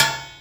描述：这些是由锅，平底锅等炊具记录的声音......它们由金属和木棒制成。用Stagg PGT40 麦克风，Digidesignmbox（原始） 小型隔离室中的波形记录器。文件是 单声道，16,44khz.Processed with wave editor.Pack，设置“syd”。
Tag: 打击乐 SFX